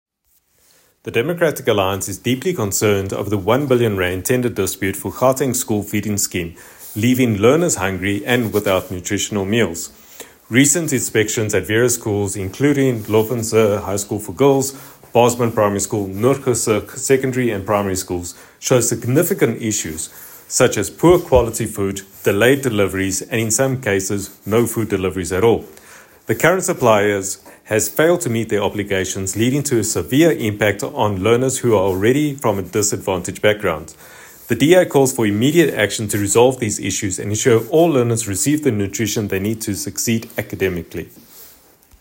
Note to Editors: Please find English soundbite by Sergio Isa Dos Santos MPL